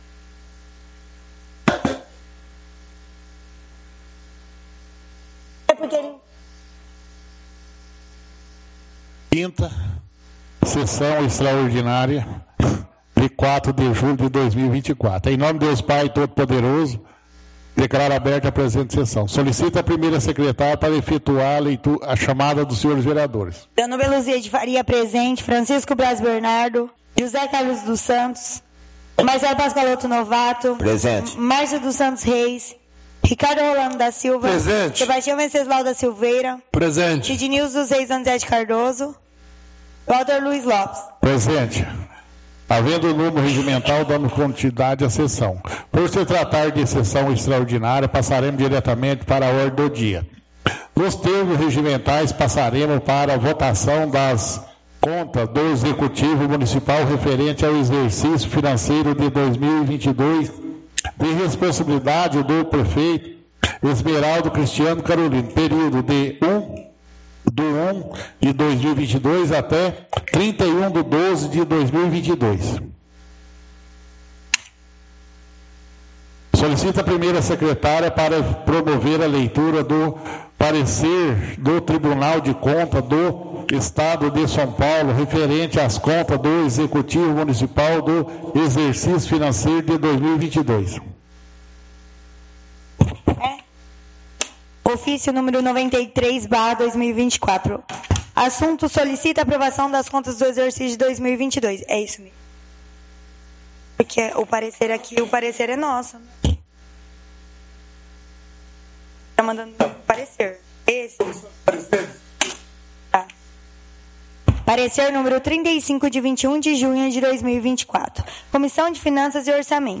Áudio da 5ª Sessão Extraordinária – 04/07/2024